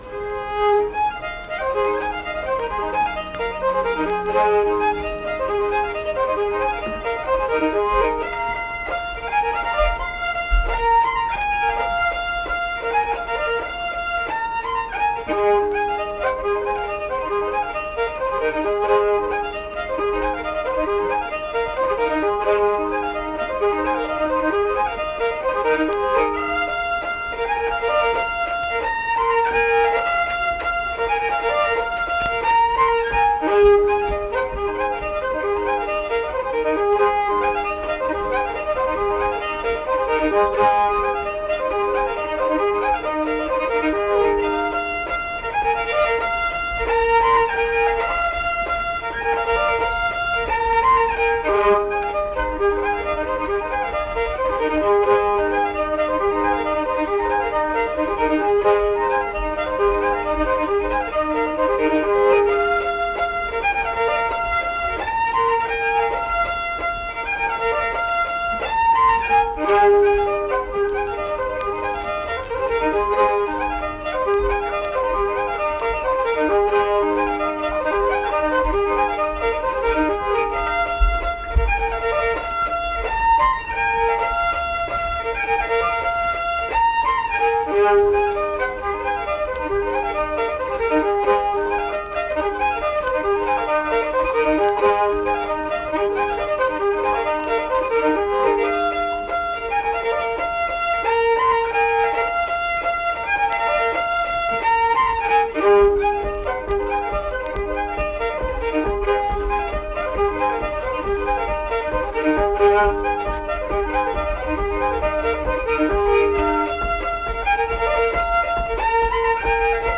Unaccompanied fiddle music
Instrumental Music
Fiddle
Pocahontas County (W. Va.), Marlinton (W. Va.)